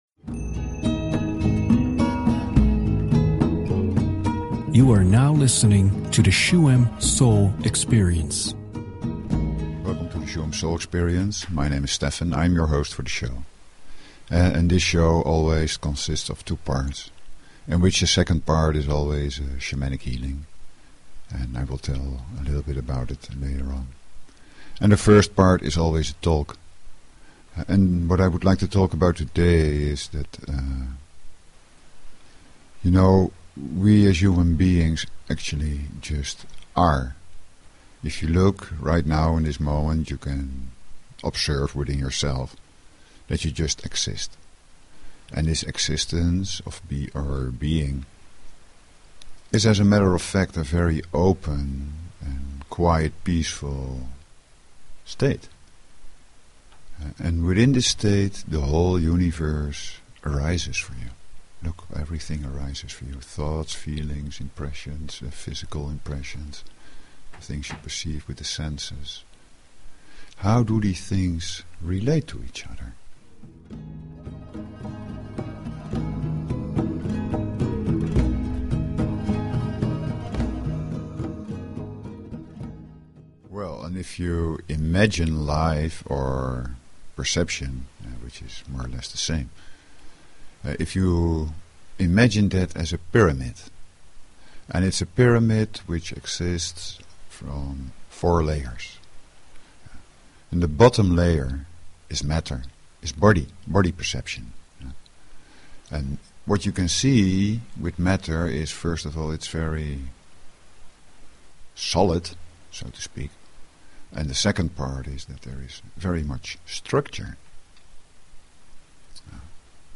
Talk Show Episode, Audio Podcast, Shuem_Soul_Experience and Courtesy of BBS Radio on , show guests , about , categorized as
The second part of the show is a Shamanic Healingmeditation.
To optimally attend the ritual, it is recommended to listen to it through headphones and not to do anything else.